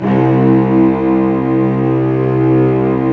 Index of /90_sSampleCDs/Roland LCDP13 String Sections/STR_Vcs II/STR_Vcs6 f Amb